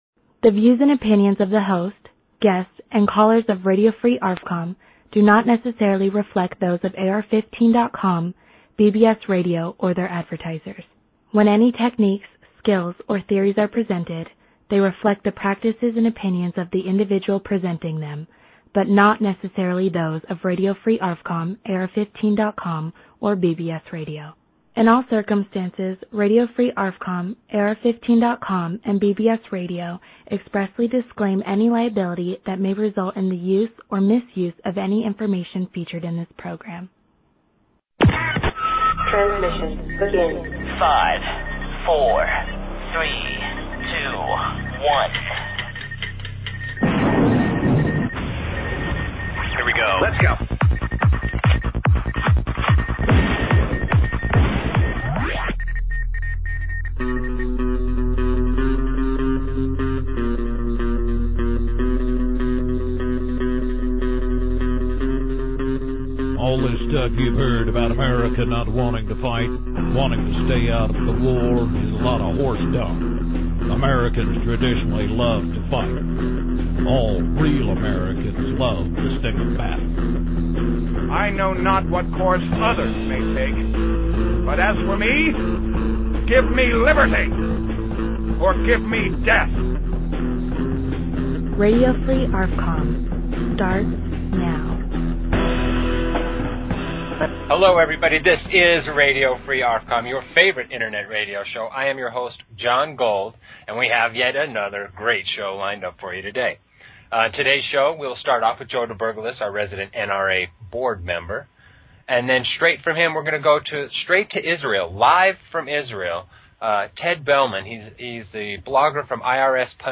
Talk Show Episode, Audio Podcast, Radio_Free_ARFCOM and Courtesy of BBS Radio on , show guests , about , categorized as